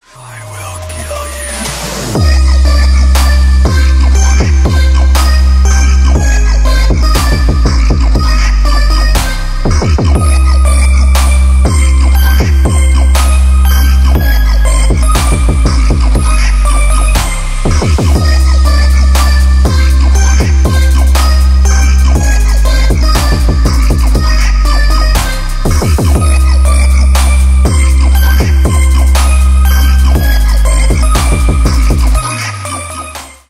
bass boosted
страшные , трэп